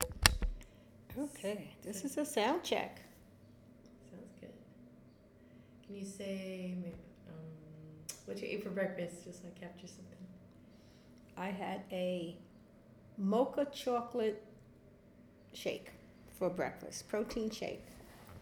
This interview is available in-person only.